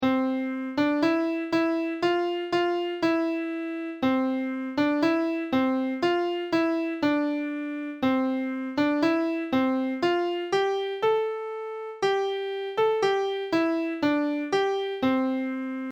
This is an action song.